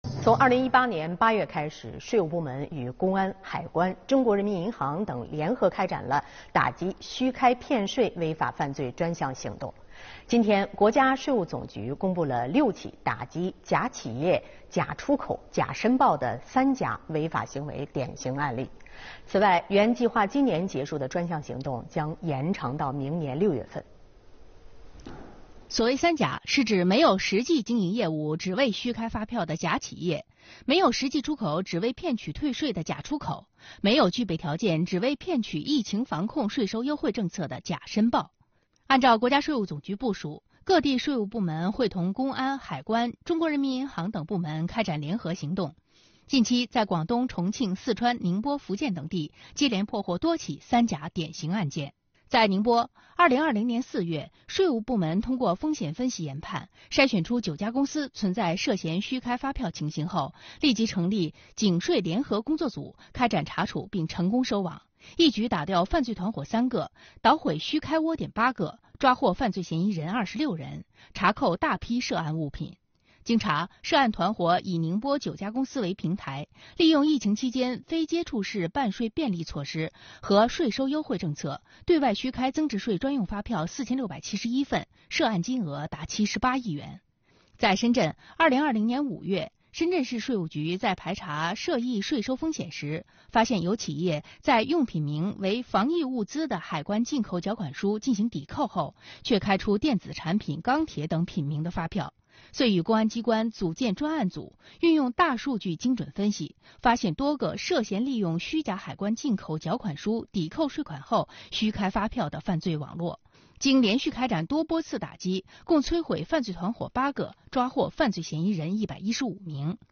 视频来源：央视《新闻直播间》